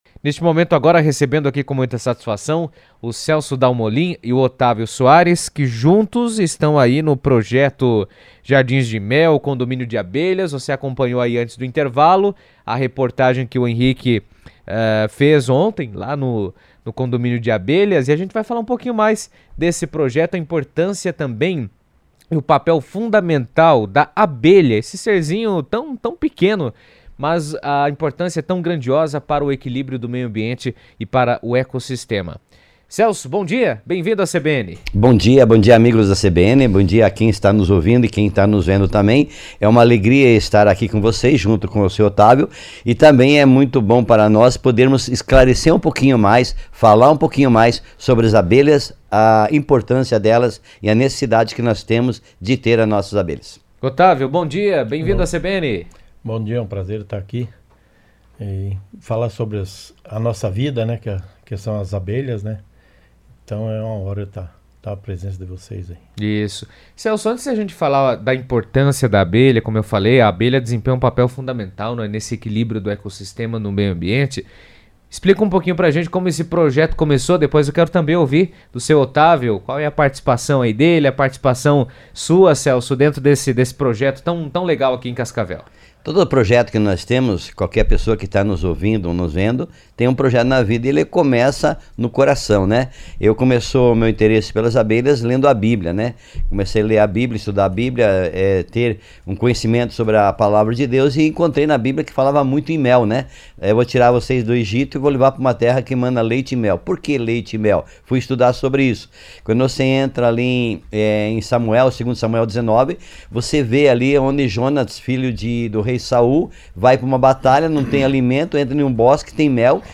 estiveram na CBN falando sobre as iniciativas e a importância delas para a biodiversidade.